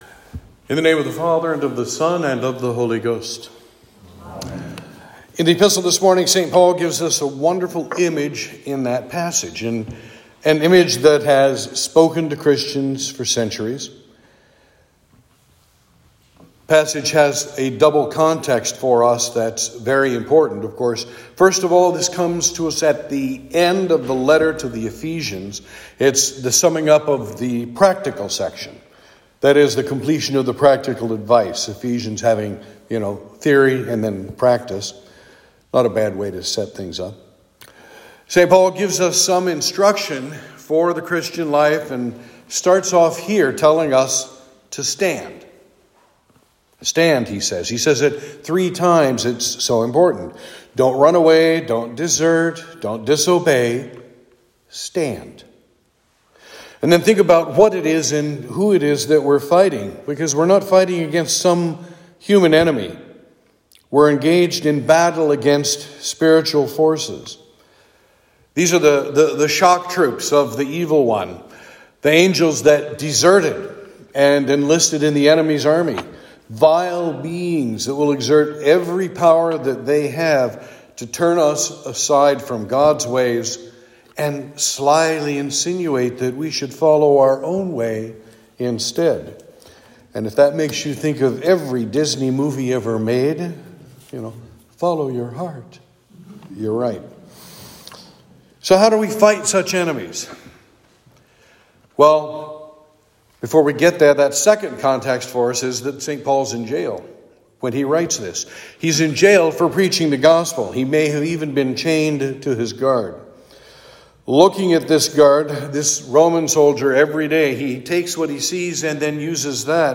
Sermon for Trinity 21